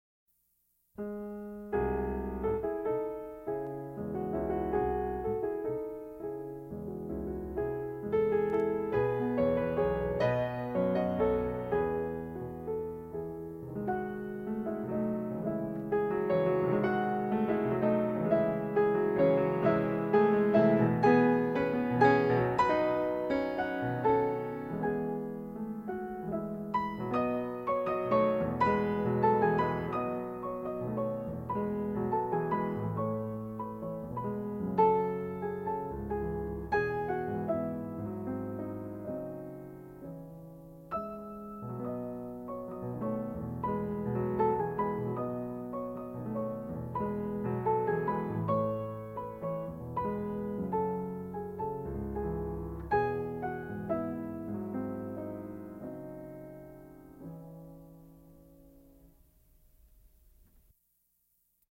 Sixteen Waltzes for piano, four hands (Op. 39), No. 16 in C-sharp minor